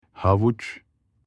Havuc.mp3